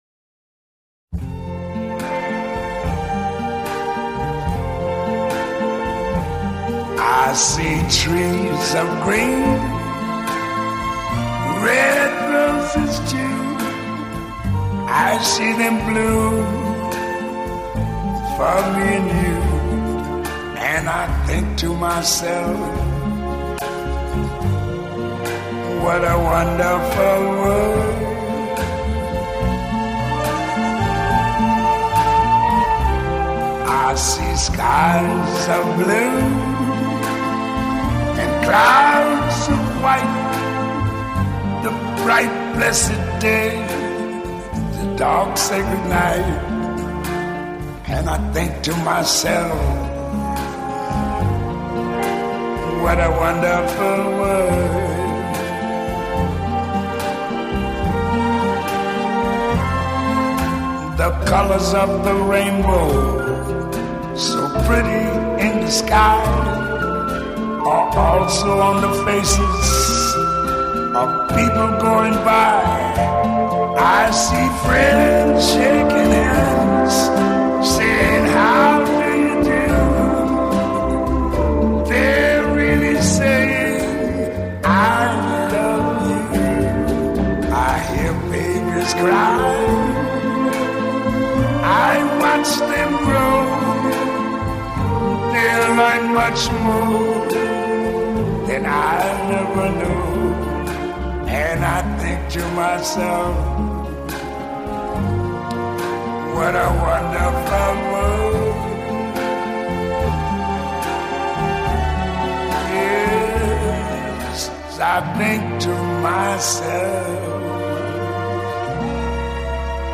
Жанр: tradjazz